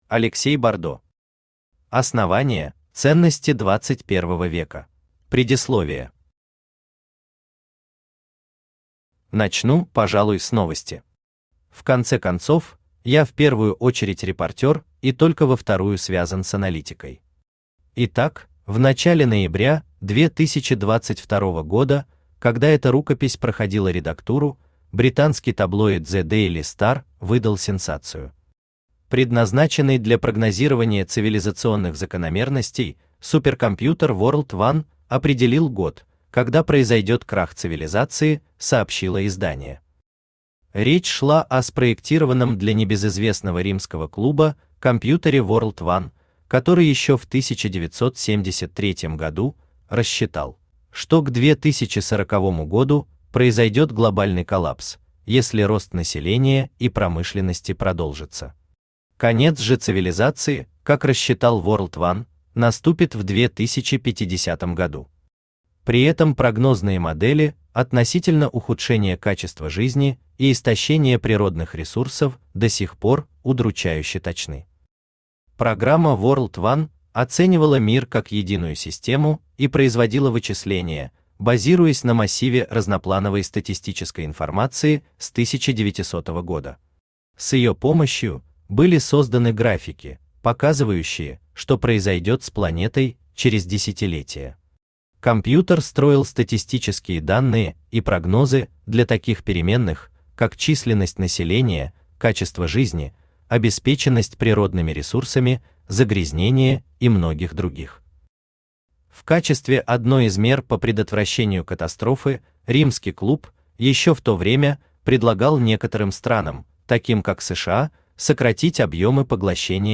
Аудиокнига Основание: ценности XXI века | Библиотека аудиокниг
Aудиокнига Основание: ценности XXI века Автор Алексей Бардо Читает аудиокнигу Авточтец ЛитРес.